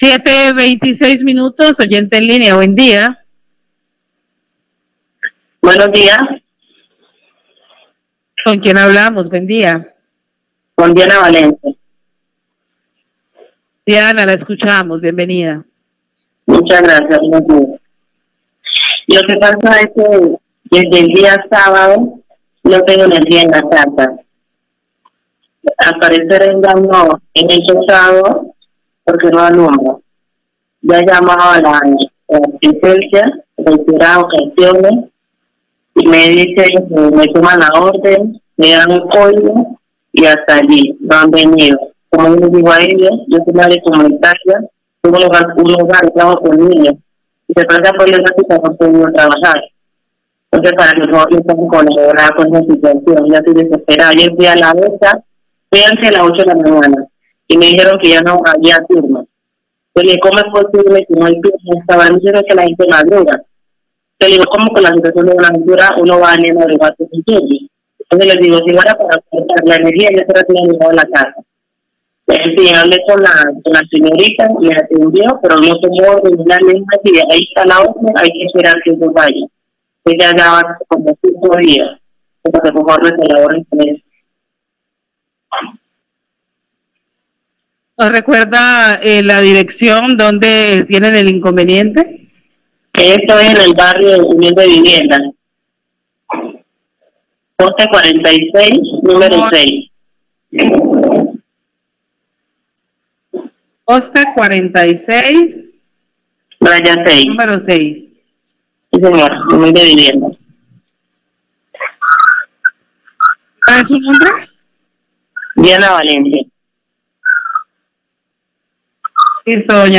Queja oyente del barrio Unión de Vivienda por corte de energía en su casa
Radio